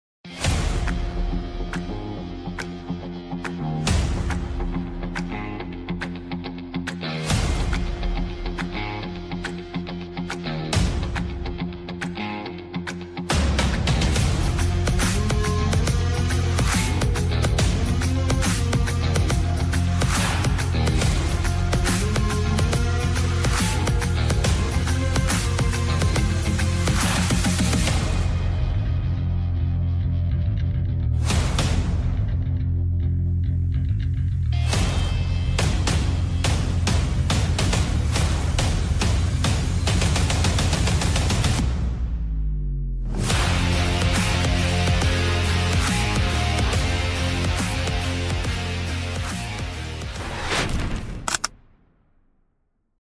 Introducing the Mp3 Sound Effect Reverse with confidence! Introducing the Abrams Backup Alarm – your audible shield for safety. Beep beep your way to enhanced awareness.